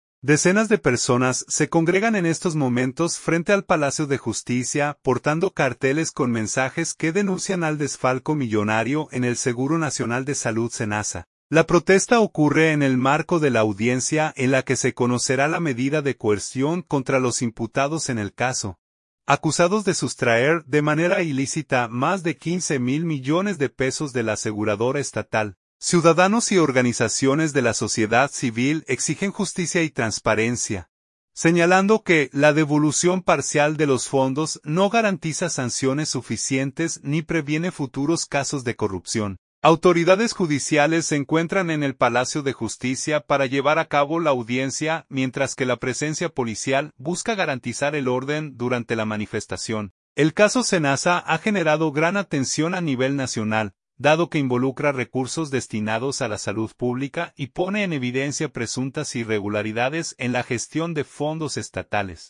Manifestantes exhiben carteles frente al Palacio de Justicia por caso SENASA
Decenas de personas se congregan en estos momentos frente al Palacio de Justicia, portando carteles con mensajes que denuncian al desfalco millonario en el Seguro Nacional de Salud (SENASA).